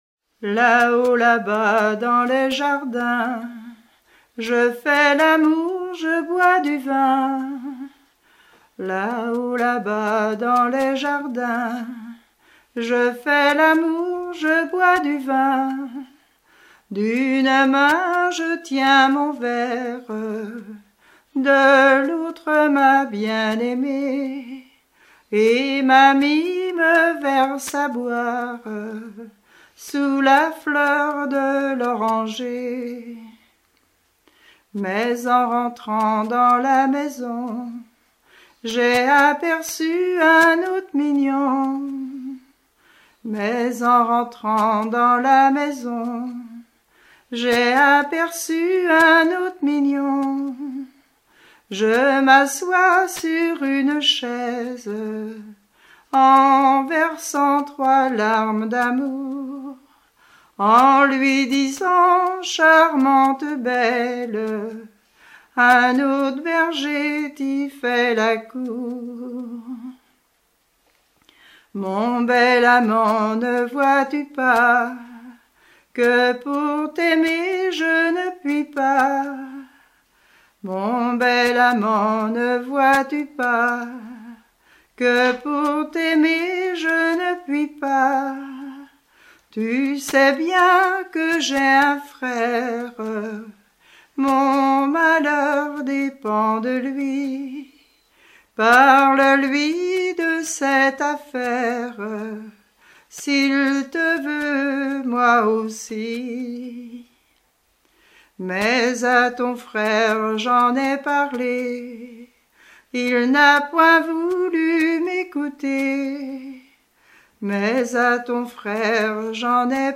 chanteur(s), chant, chanson, chansonnette
Genre strophique